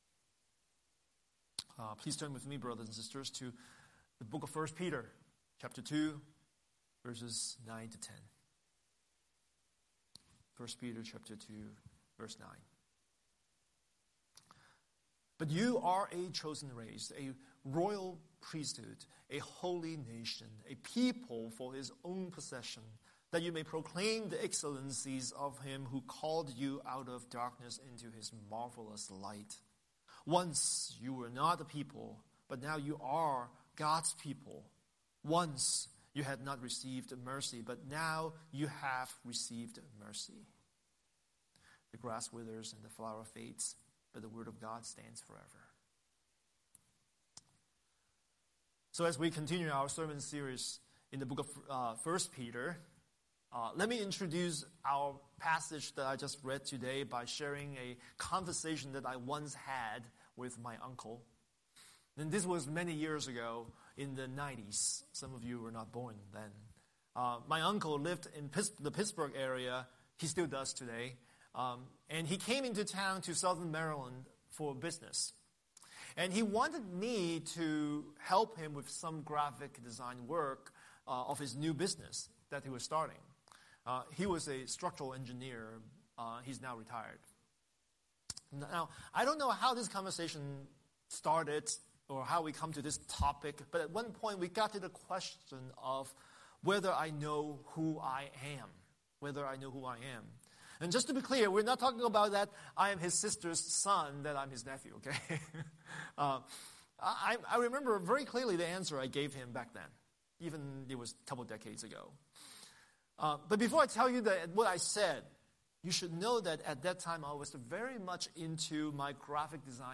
Scripture: 1 Peter 2:9–10 Series: Sunday Sermon